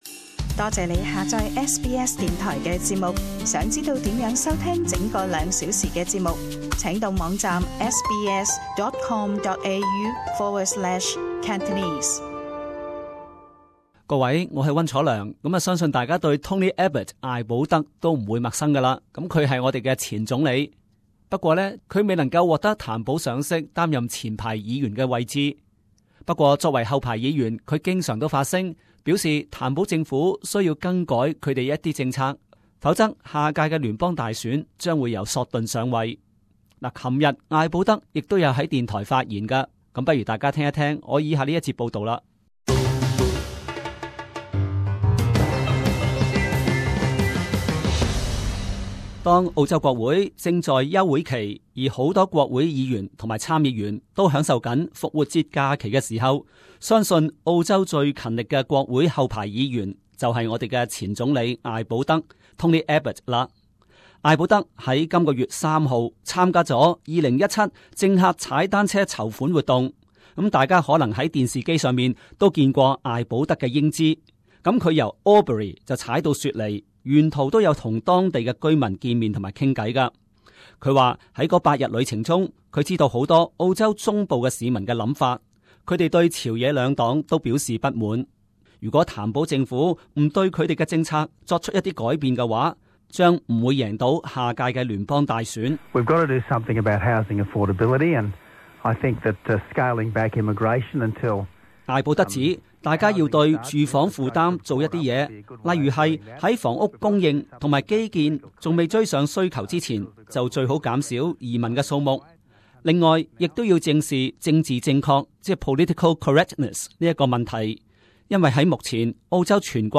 【時事報導】 艾保德：政府要贏大選就必須改變政策